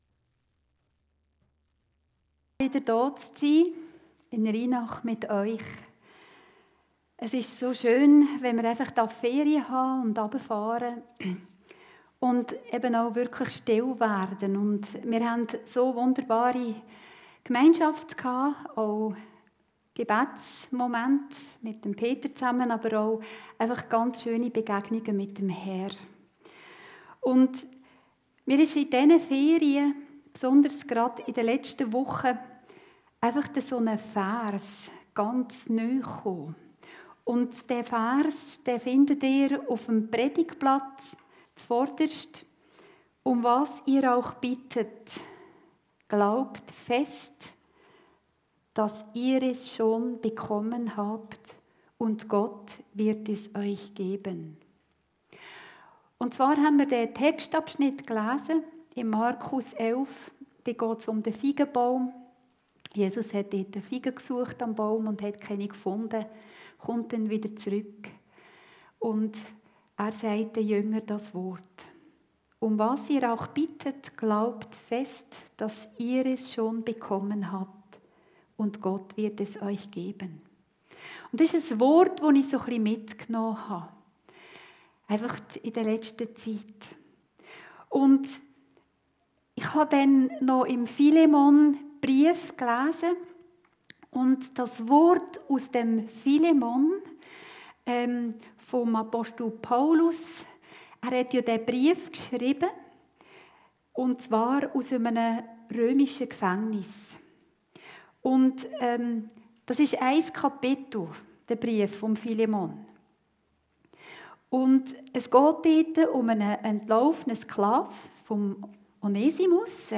Predigten Heilsarmee Aargau Süd – glaubt fest